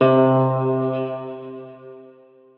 Guitar - UKA.wav